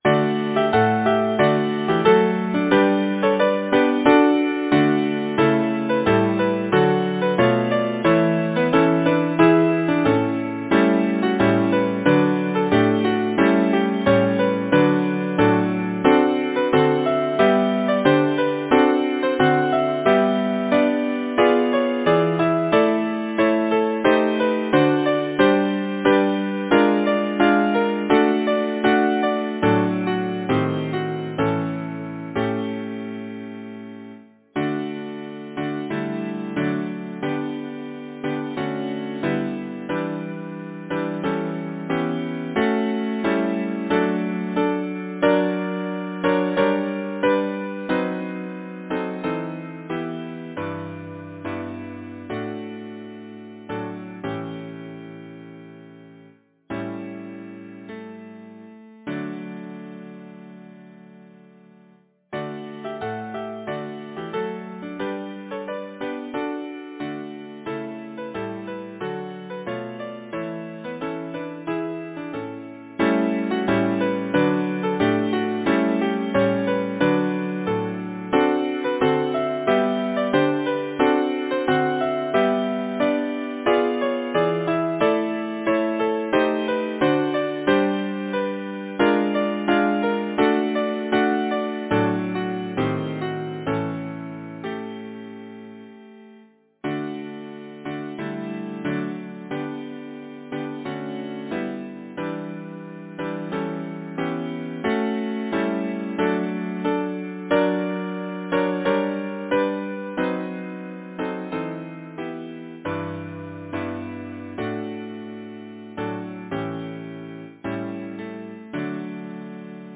Title: The light of Love Composer: Arthur Wellesley Batson Lyricist: John Bowring Number of voices: 4vv Voicing: SATB Genre: Secular, Partsong
Language: English Instruments: A cappella